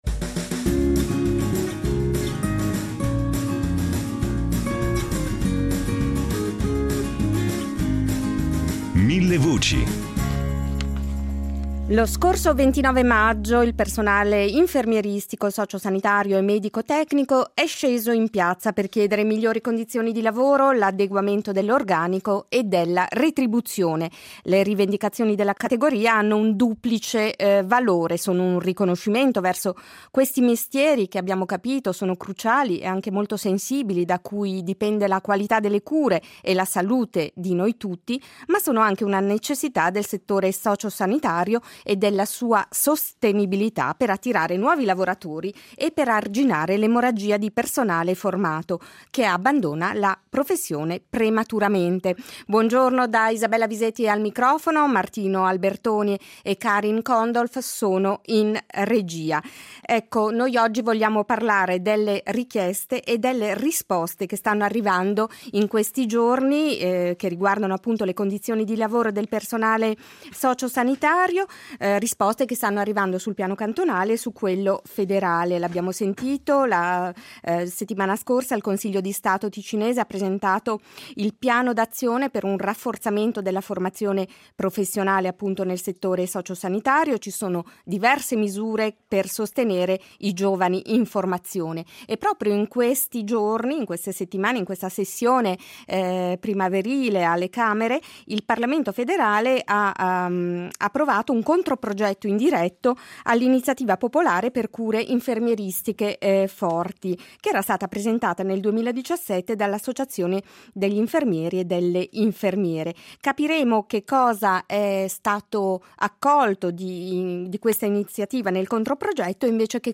Per discuterne sono ospiti: